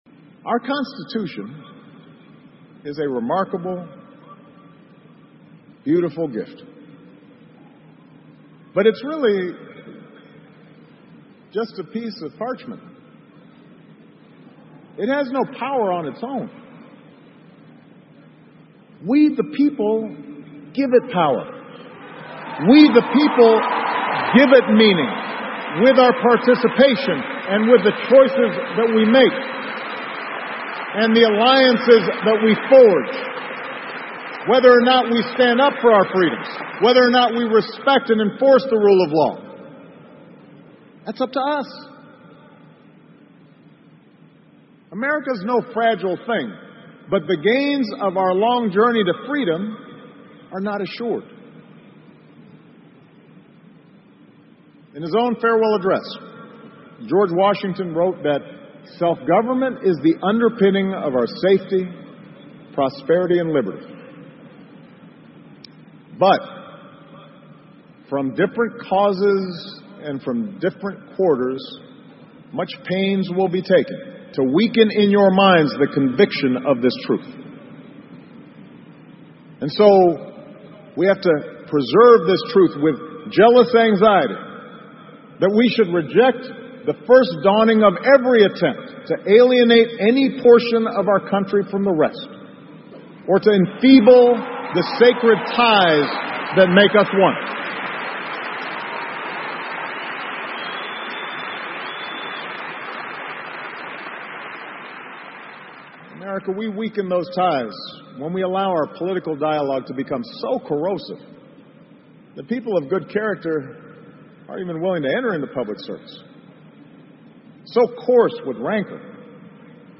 奥巴马每周电视讲话：美国总统奥巴马告别演讲(17) 听力文件下载—在线英语听力室